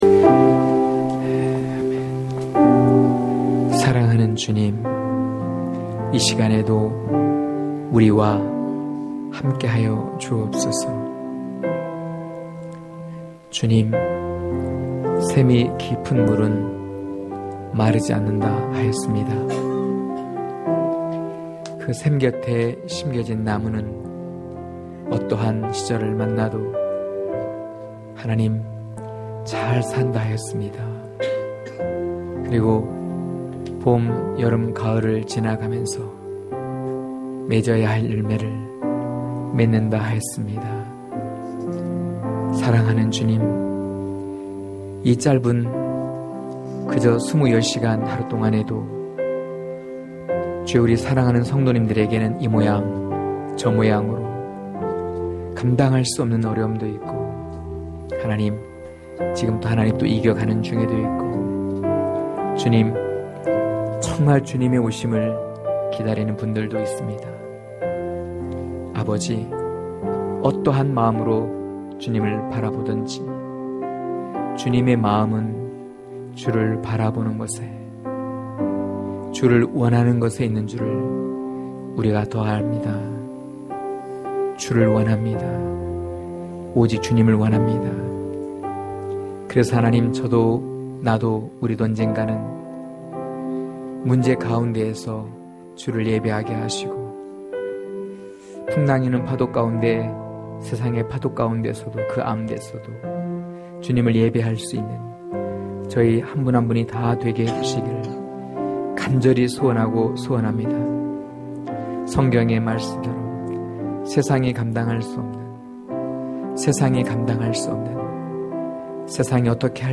강해설교 - 6.함께 가려고 뒤에 간다면 참 괜찮다!!!(요일3장1-12절)